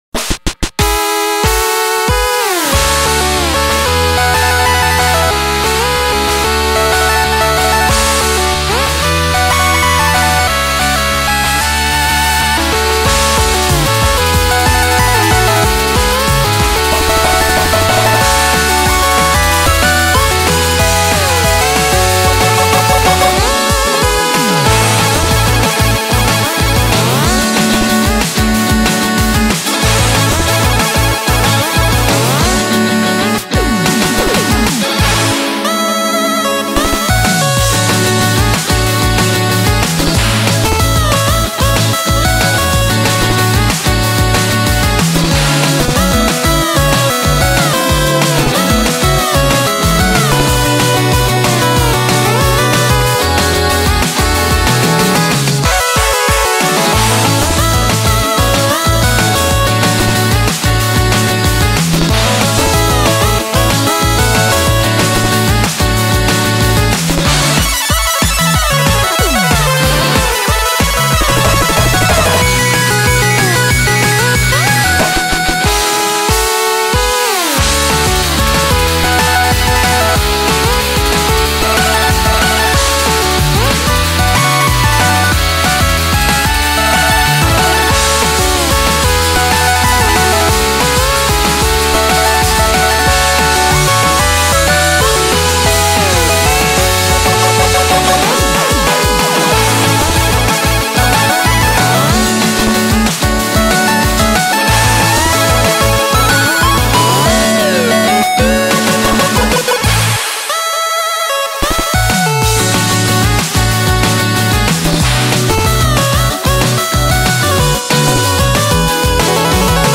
BPM93-186
Audio QualityCut From Video